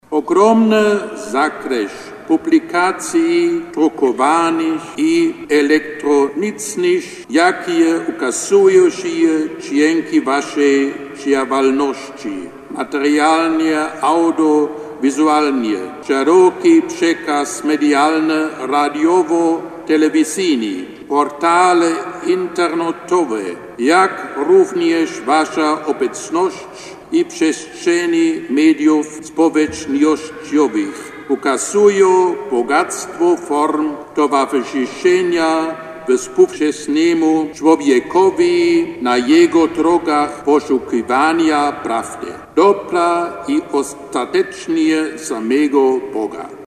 Targi są okazją, aby odkryć wartość wspólnoty, którą stanowicie – mówił Kardynał Gerhard Müller, który przewodniczył Eucharystii w ramach 28. Edycji Targów Wydawców Katolickich. Msza święta została odprawiona w intencji autorów, wydawców i czytelników w katedrze warszawsko-praskiej pw. św. Michała Archanioła i św. Floriana.